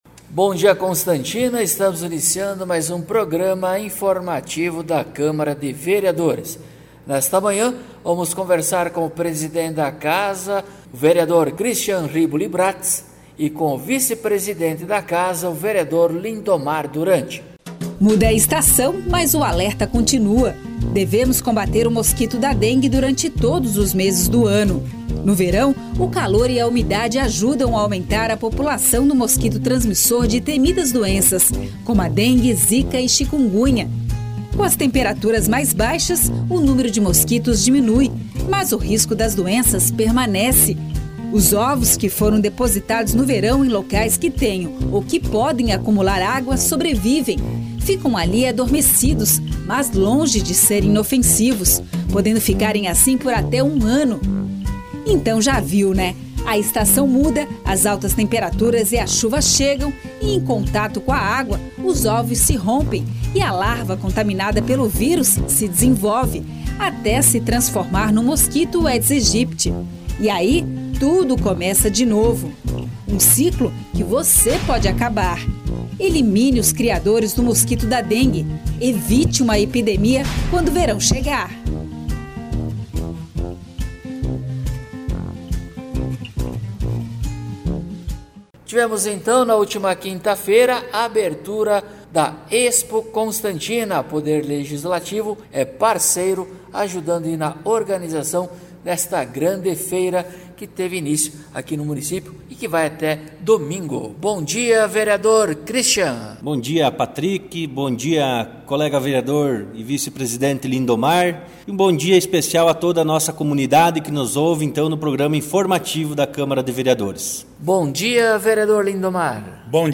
Acompanhe o programa informativo da câmara de vereadores de Constantina com o Vereador Cristian Bratz e o Vereador Lindomar Duranti.